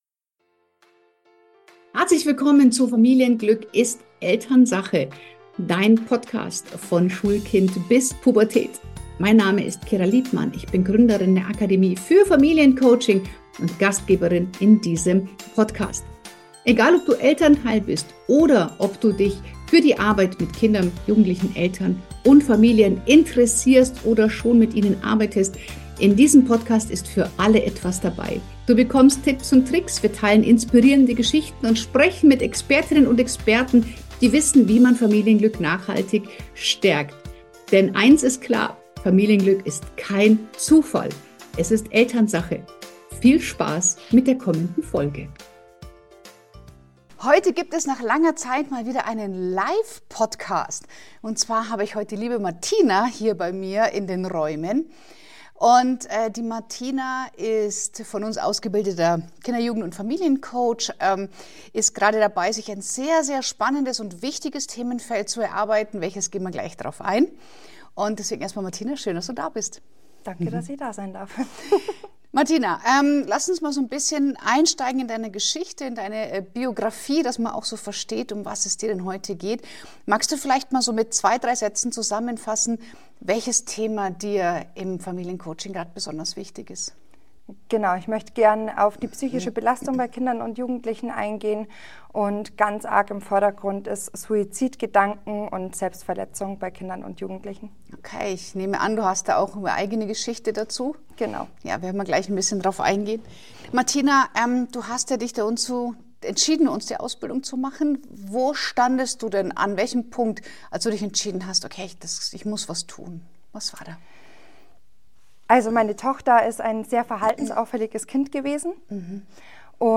Ein Gespräch über: -Warum Kinder sich selbst verletzen -Was hinter Suizidgedanken wirklich steckt -Die 5 Eskalationsstufen eines „Schreis nach Bindung“ -Warum Selbstverletzung kein „Teenie-Drama“ ist -Welche Rolle Eltern – oft unbewusst – spielen -Warum frühes Hinschauen so entscheidend ist -Wie Kliniken, Tageskliniken und professionelle Hilfe unterstützen können -Warum auch Mobbing ein systemischer Prozess ist -Und weshalb Heilung bei den Eltern beginnen darf Wir sprechen offen über Schuldgefühle, Hilflosigkeit, Traumaweitergabe und den Mut, Verantwortung zu übernehmen.